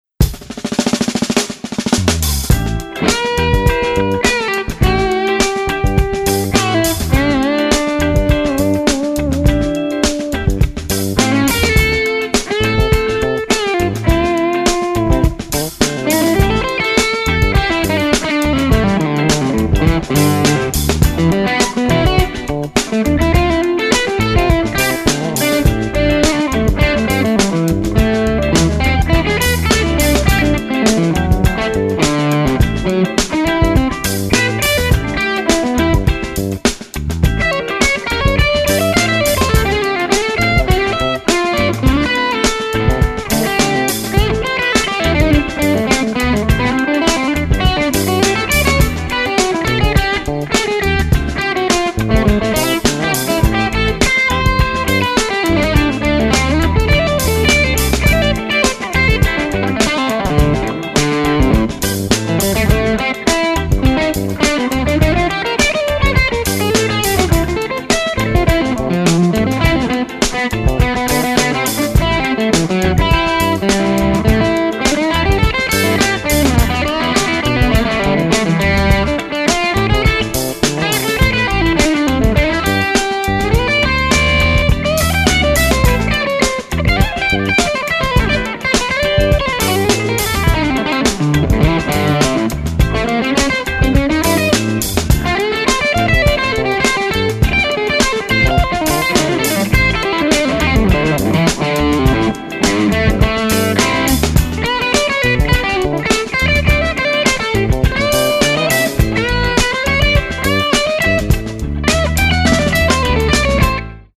Sorry for crappy playing.